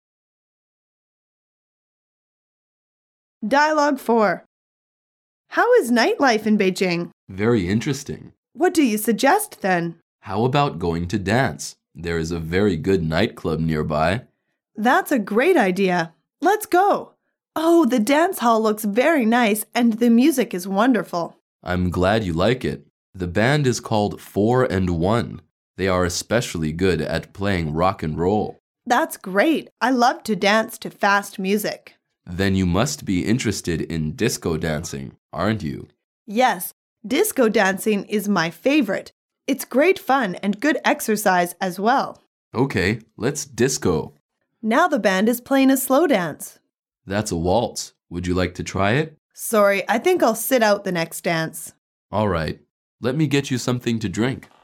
Dialoug 4